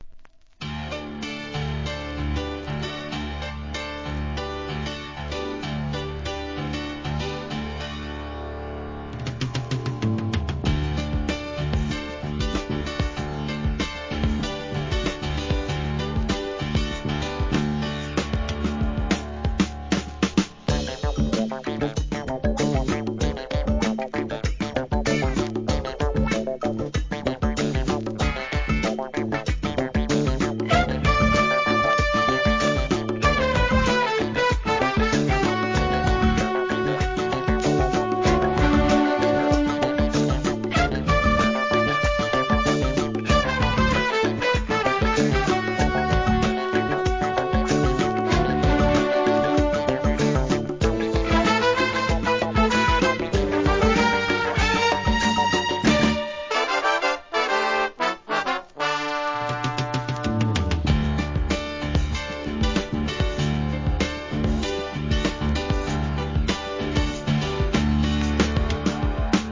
1. SOUL/FUNK/etc...